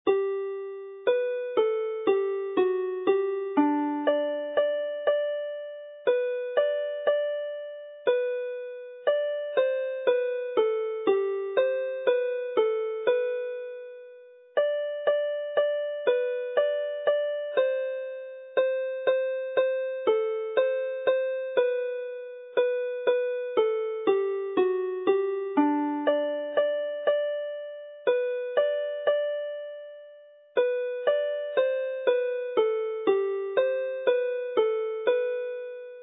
Ar Gyfer Heddiw'r Bore - tenor/alto
For this morning - top harmony